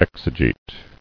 [ex·e·gete]